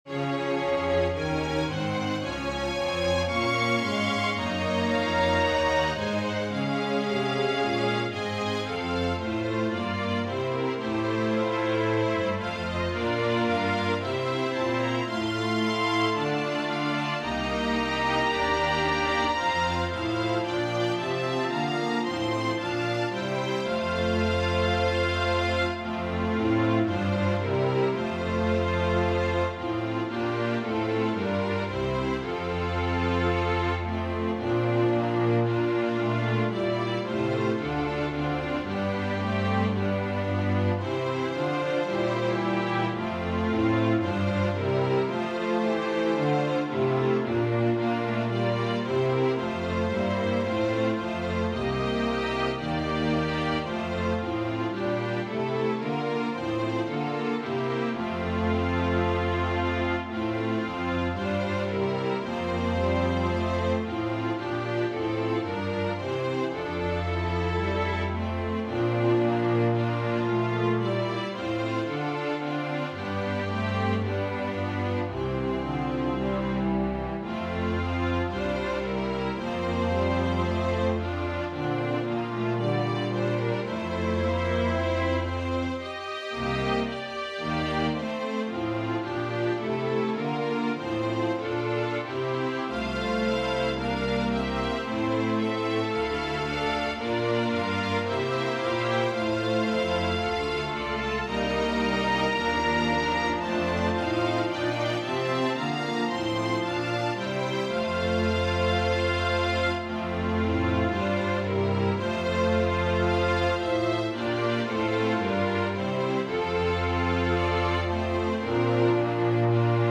Organ/Organ Accompaniment
I'll settle for what I have as it turns out its two organist playing what we hear when performed by the choir.